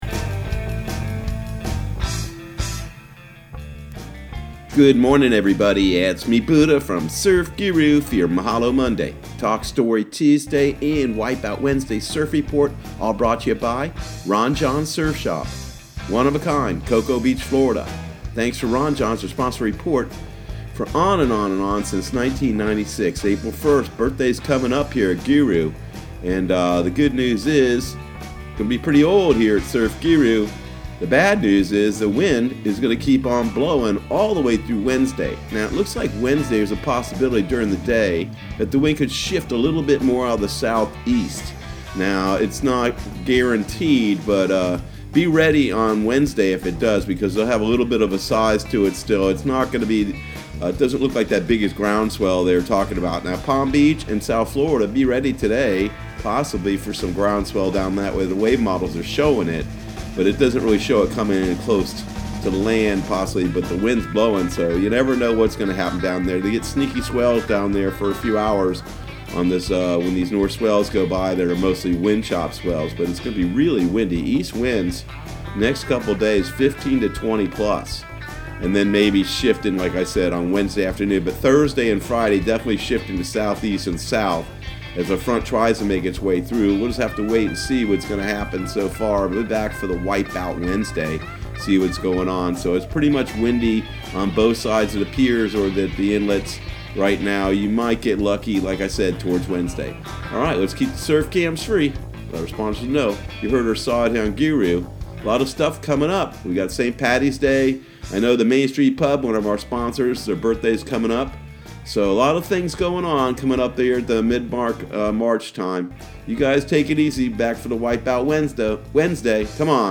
Surf Guru Surf Report and Forecast 03/09/2020 Audio surf report and surf forecast on March 09 for Central Florida and the Southeast.